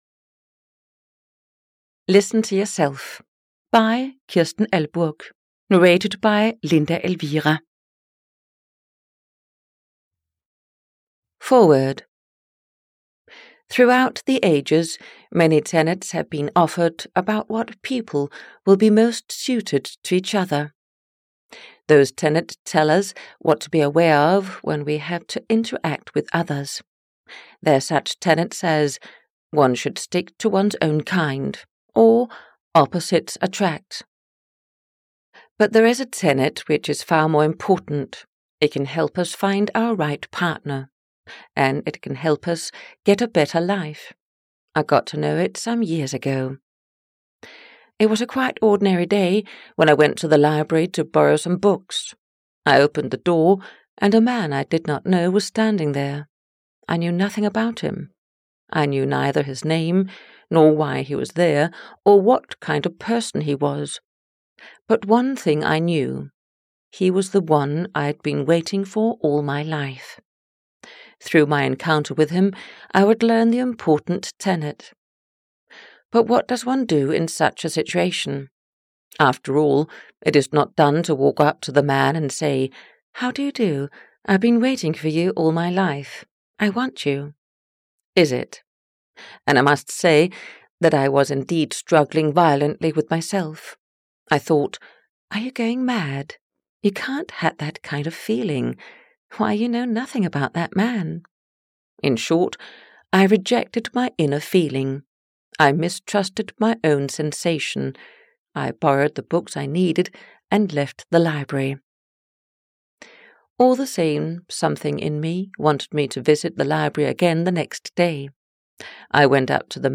Audio knihaListen to Yourself (EN)
Ukázka z knihy